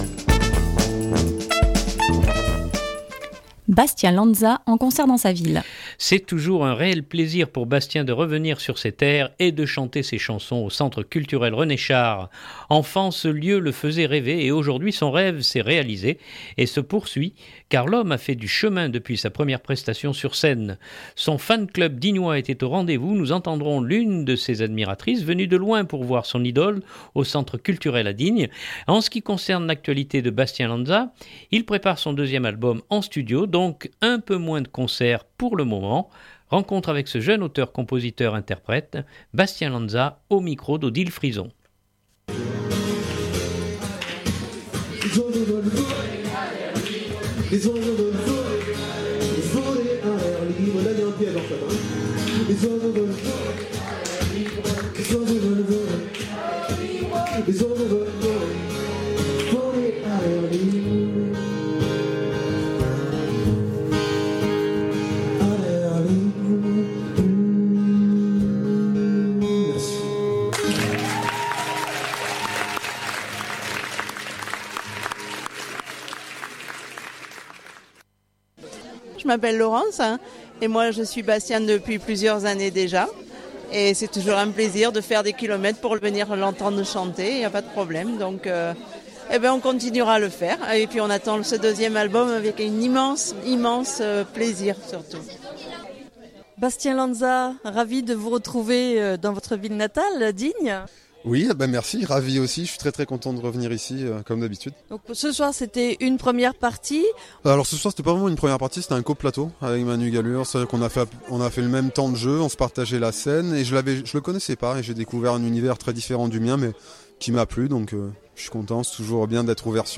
Son fan club était au rendez-vous, nous entendrons l’une de ses admiratrices venue de loin pour voir son idole au Centre culturel à Digne.